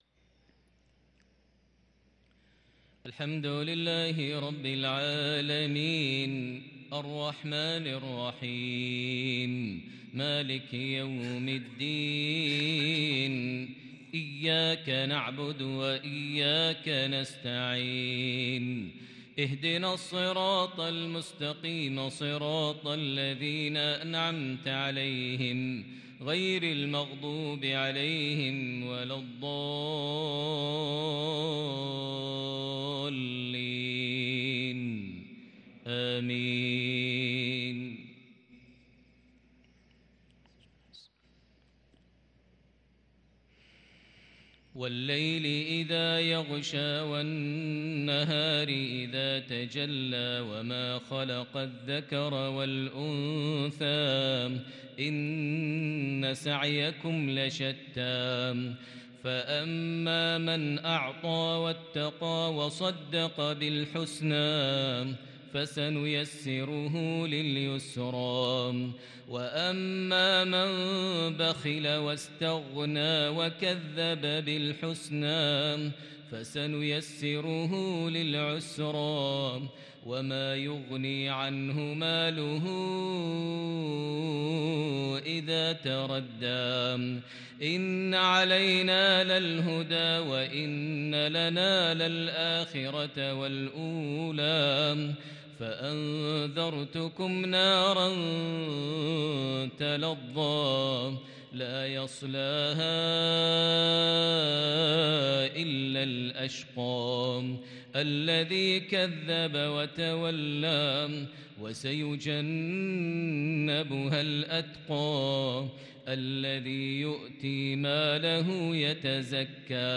صلاة المغرب للقارئ ماهر المعيقلي 27 جمادي الأول 1444 هـ